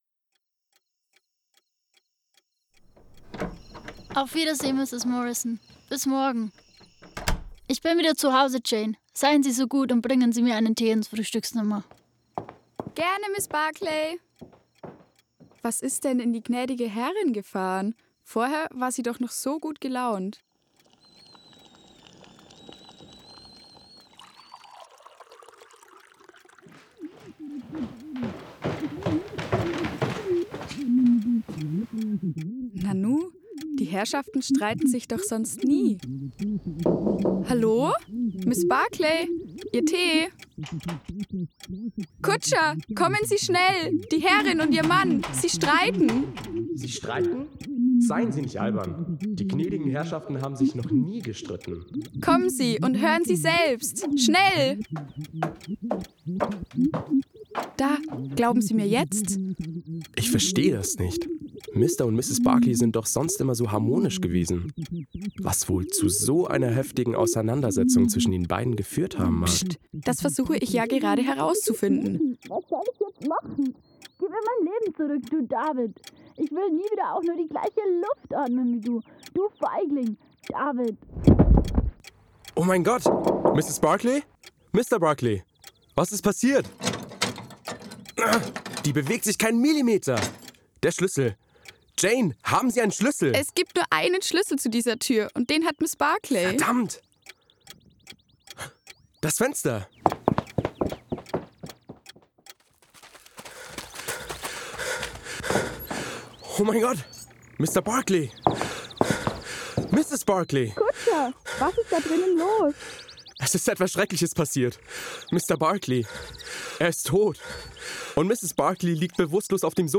Hoerspiel_SherlockHolmes-DerKrummeMann.mp3